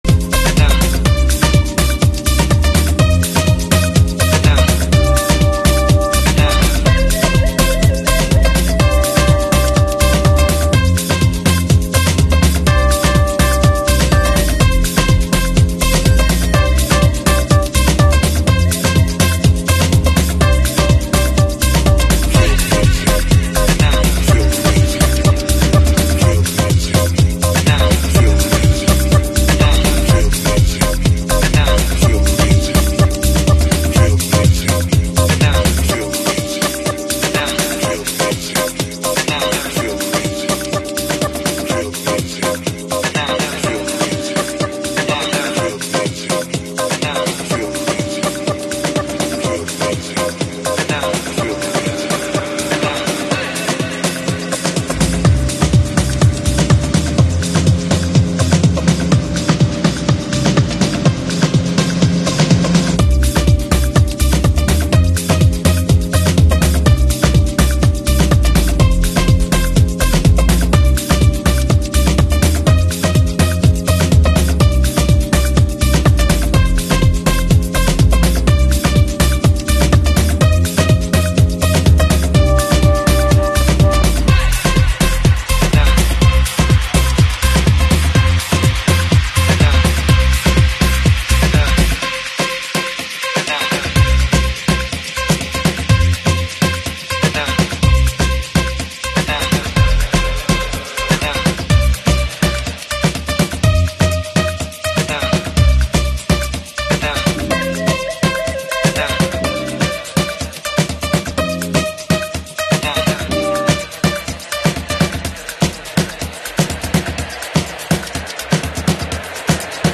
Deep/Tech House